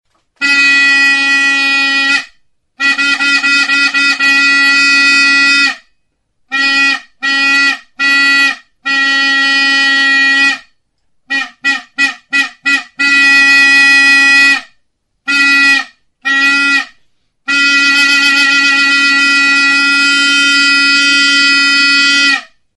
Music instrumentsTURUTA; TROMPETA DEPORTIVA
Membranophones -> Mirliton
Recorded with this music instrument.
Plastiko gorrizko tutua da.